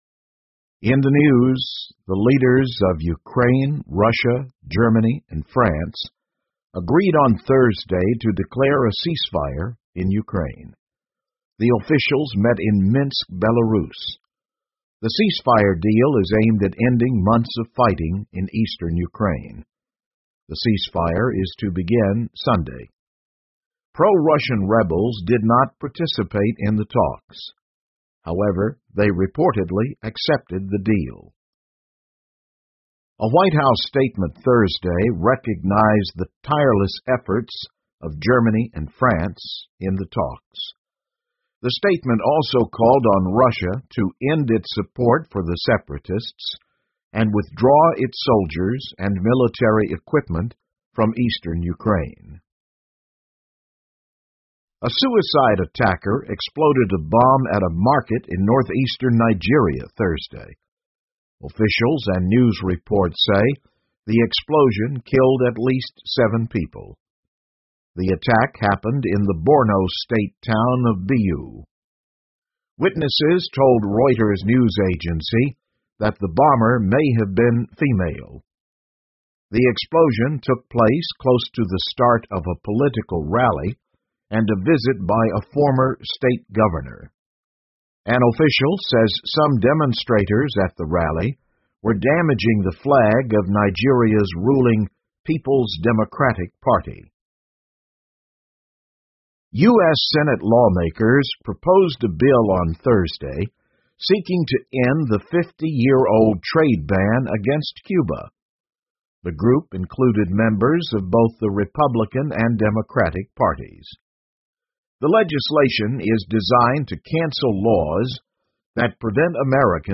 VOA慢速英语2015 乌克兰东部达成停火协议 听力文件下载—在线英语听力室